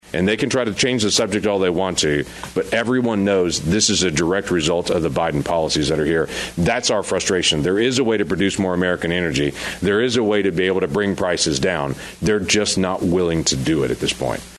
In a press conference on Wednesday, Lankford and fellow Senate Republicans defended American energy production and called out the Biden Administration for what they say are anti-energy policies that have hurt energy production and supply in the US.
In his address, Sen. Lankford said: